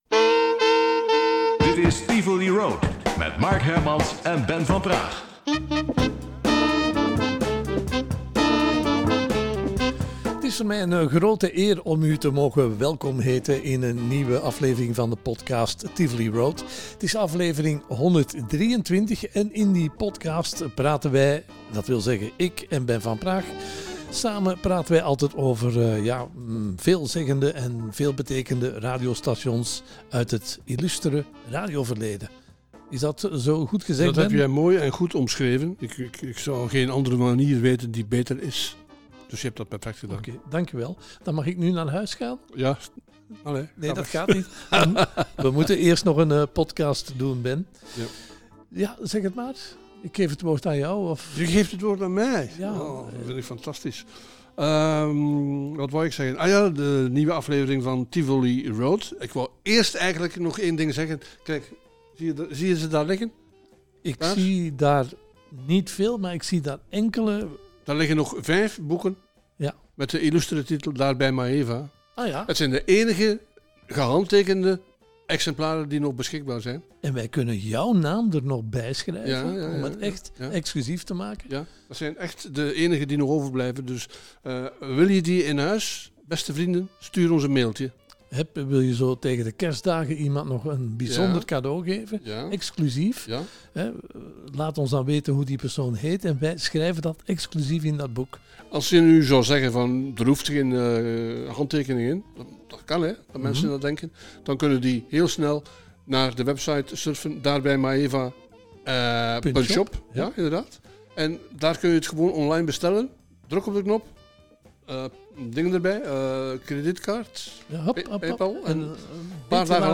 in een opname van Radio Veronica uit 1973. De hete adem van de overheid was voelbaar in de nek van de medewerkers.